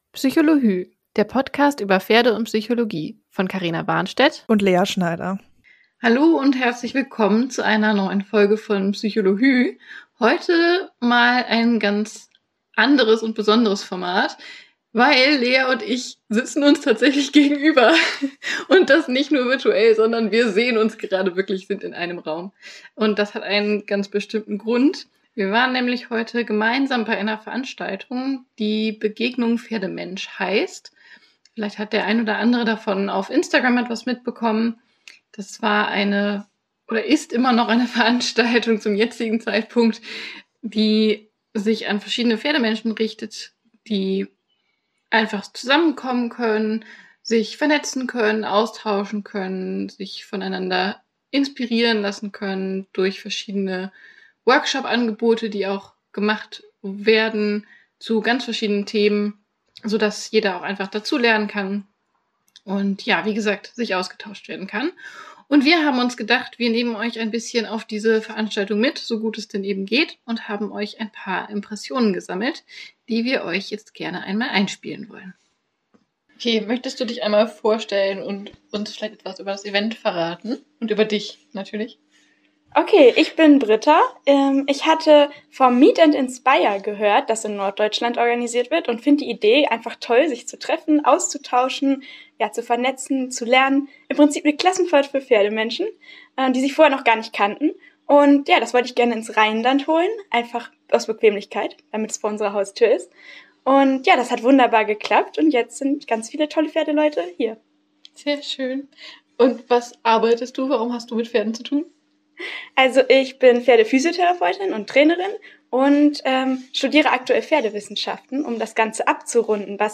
Wir sprechen vor Ort mit verschiedenen Teilnehmern über ihre Erfahrungen mit Pferden und auf dem Event. Außerdem berichten wir über unseren Workshop, den wir angeleitet haben, bei dem es um Raumwahrnehmung und Kommunikation ging.